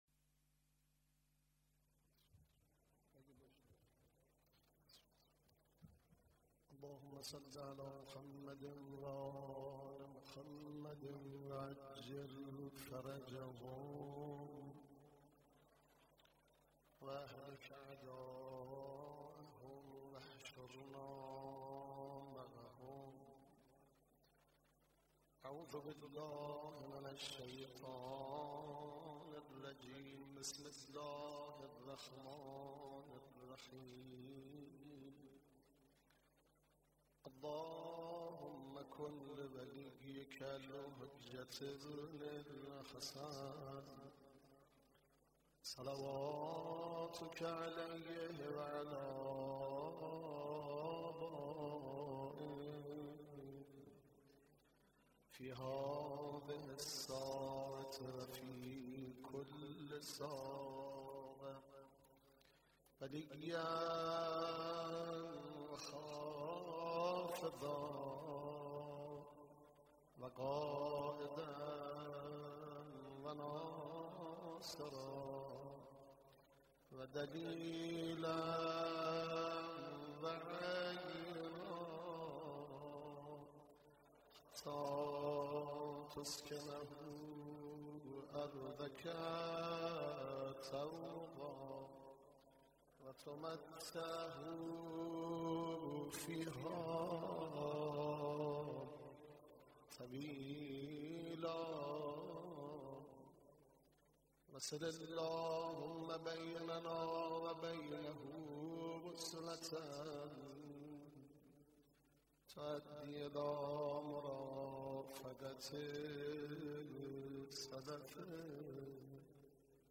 مداحی در اولین شب عزاداری در حسینیه امام خمینی / محرم ۱۴۳۴
مراسم عزاداری امام حسین (علیه‌السلام)